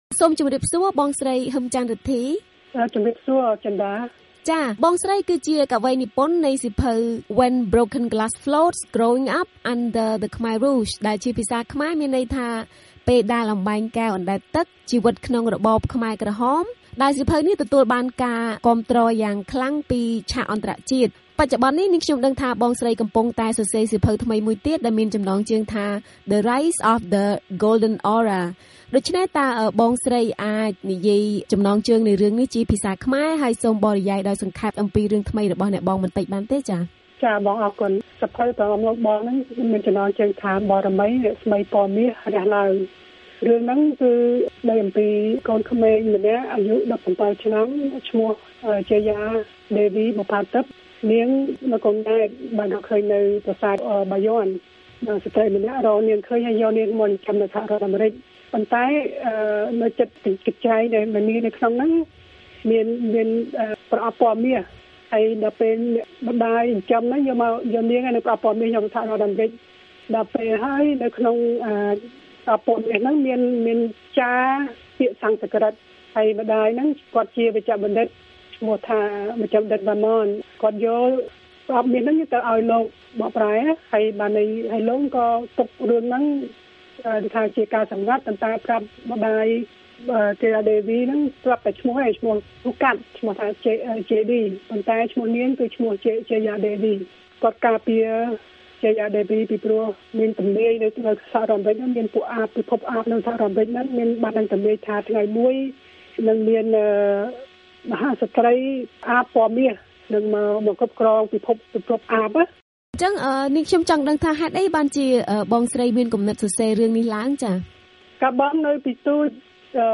បទសម្ភាសន៍ VOA៖ អ្នកនិពន្ធខ្មែរអាមេរិកាំងម្នាក់និពន្ធប្រលោមលោកអាបបែបមនោសញ្ចេតនា